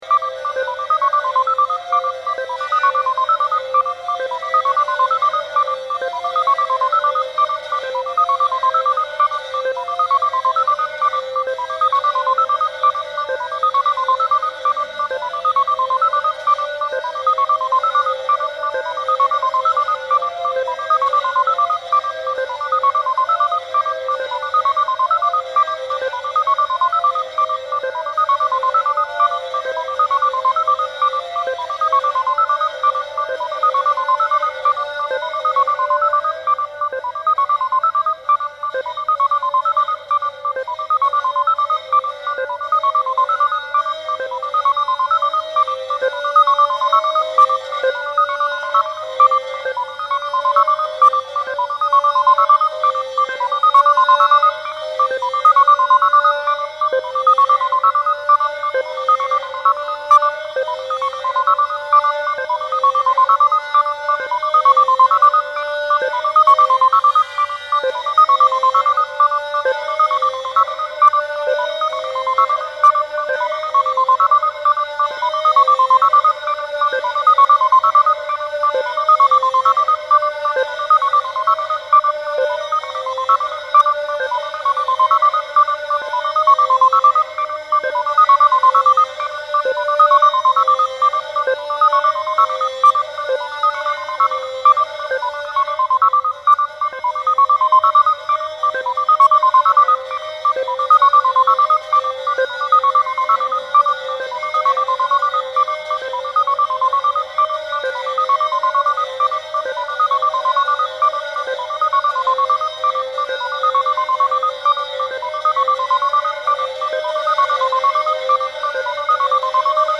Click above for exerpts of electronic music composed by Lars Akerlund (seweden) and performed live on traditional Thai instrument and computer